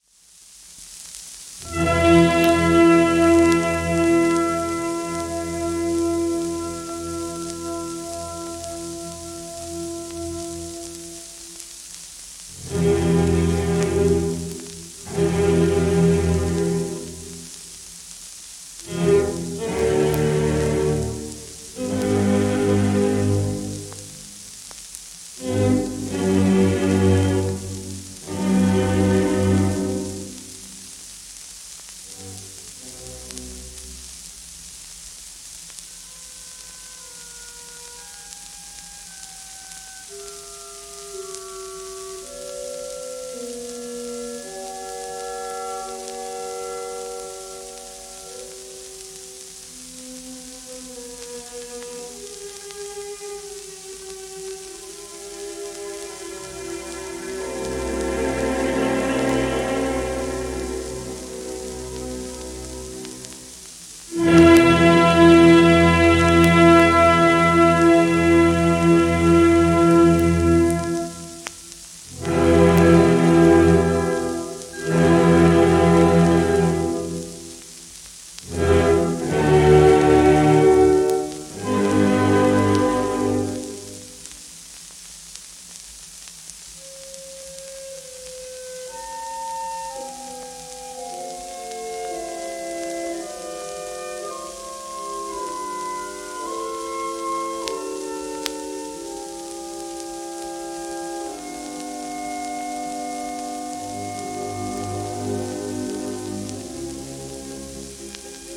1933年頃録音